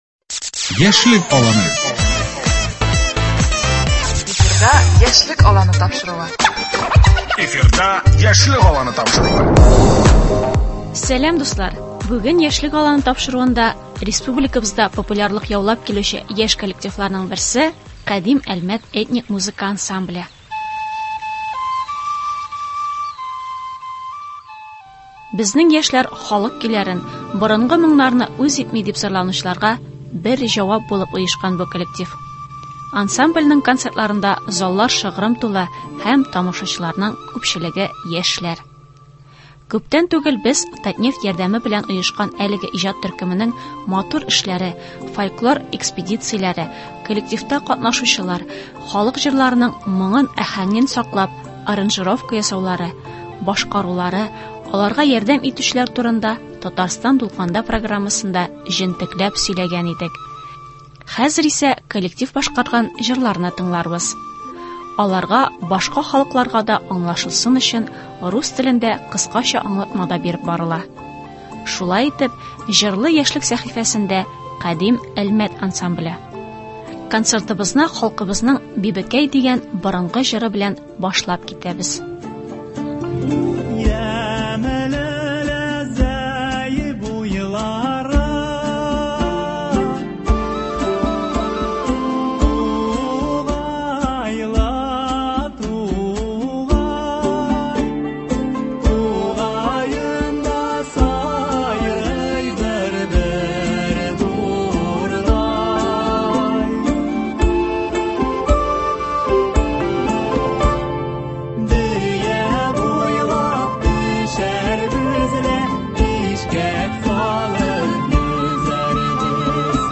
Бүген “Яшьлек аланында” республикабызда популярлык яулап килүче яшь коллектиларның берсе – “Кадим Әлмәт” этник музыка ансамбле.
Хәзер исә коллектив башкарган җырларны тыңларбыз.